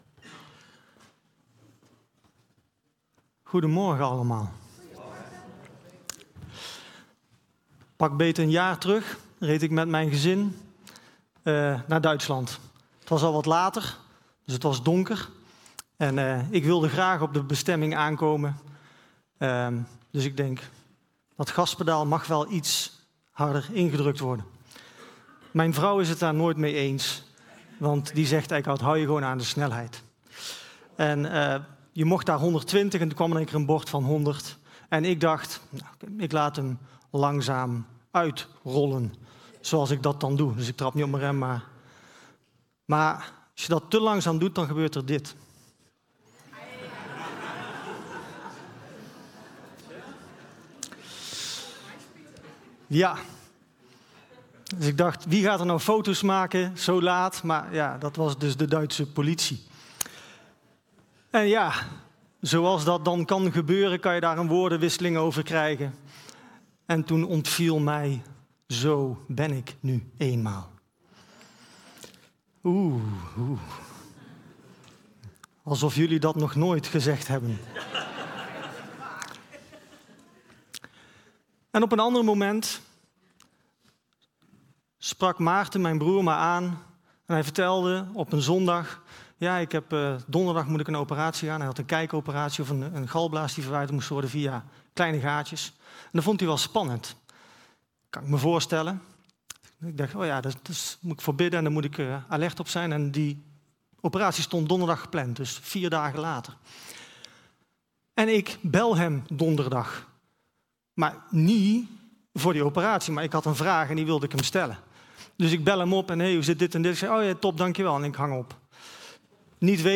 Toespraak 11 mei: Zo ben ik nu eenmaal!